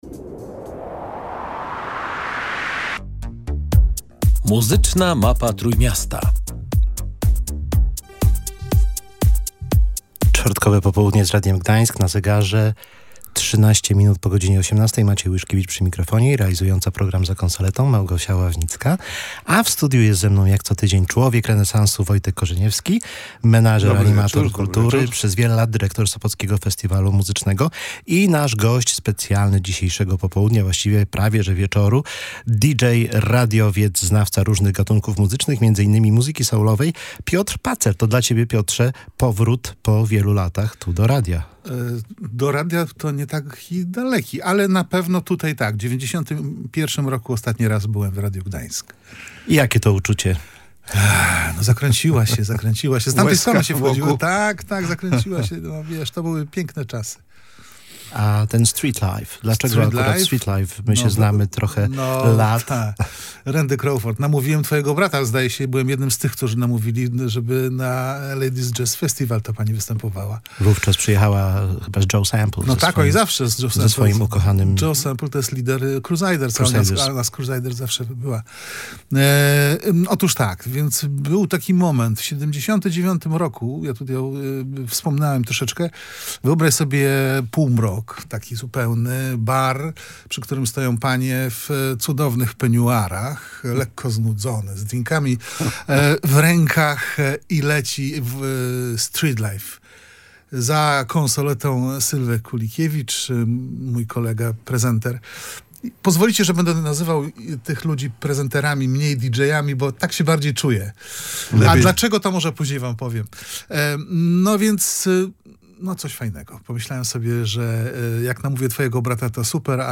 Rozmowa z legendarnym prezenterem muzycznym